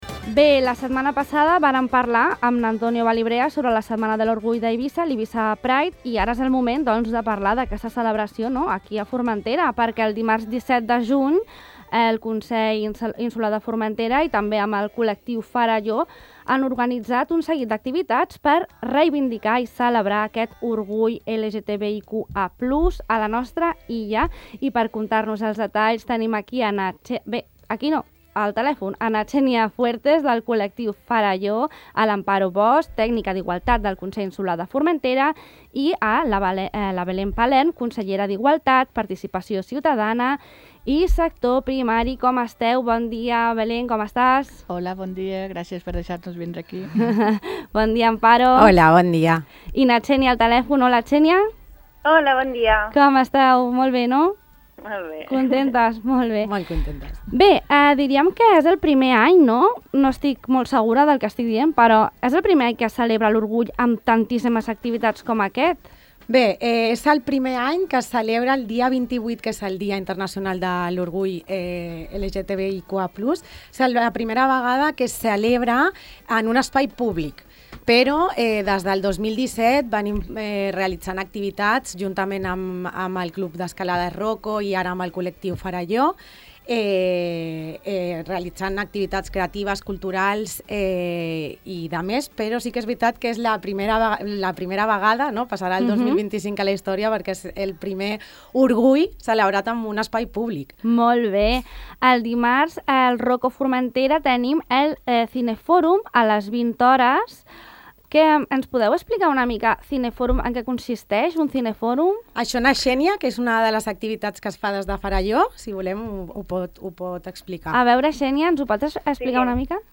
Podeu escoltar l’entrevista sencera aquí i consultar les activitats més abaix: